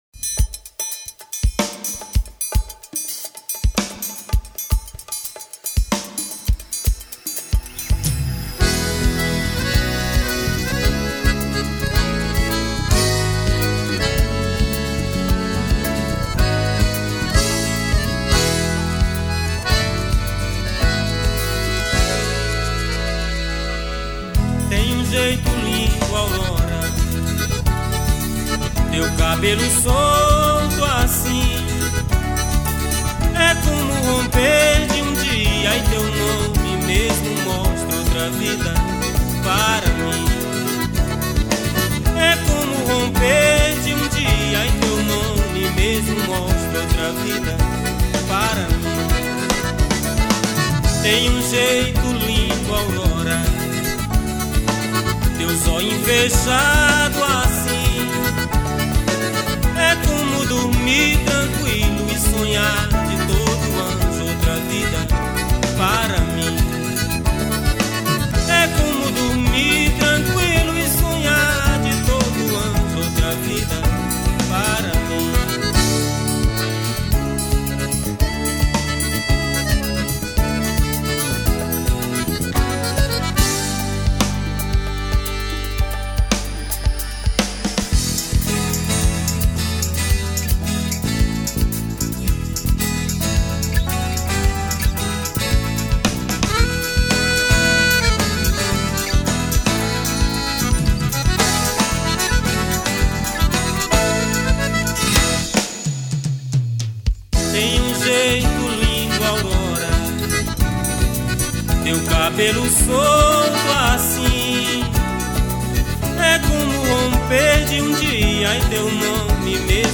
Guitarra, Viola
Bateria
Acoordeon, Teclados
Baixo Elétrico 6
Percussão